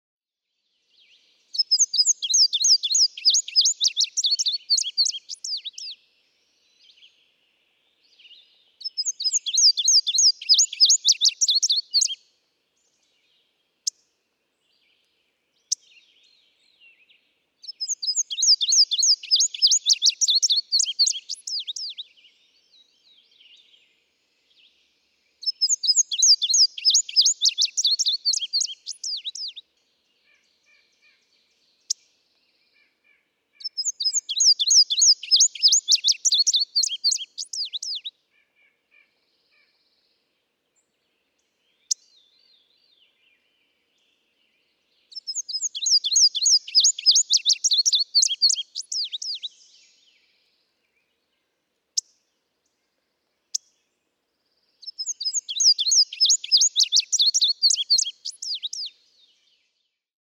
Diminuendo—indigo bunting
A gradual decrease in loudness of a song.
Quabbin Park, Ware, Massachusetts.
650_Indigo_Bunting.mp3